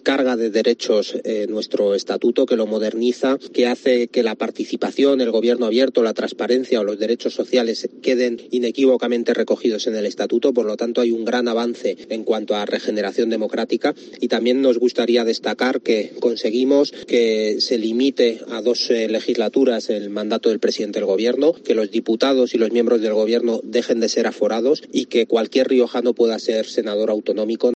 Pablo Baena, portavoz parlamentario de Ciudadanos